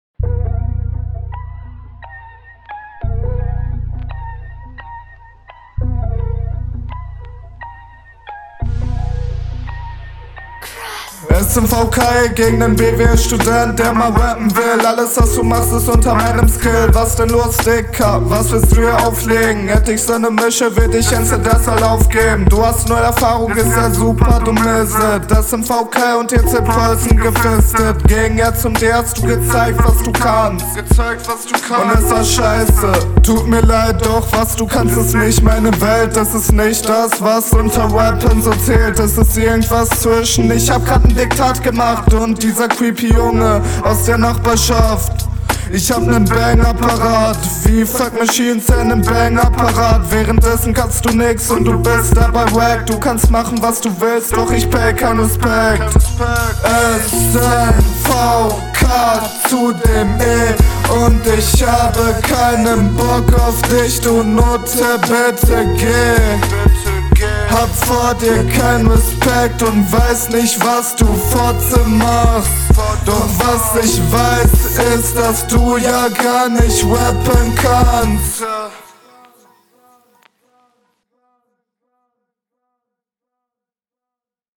Bangapparat-Line ist funny, Stimmeinsatz klingt etwas erzwungen und der Beat ist zu sehr im Hintergrund, …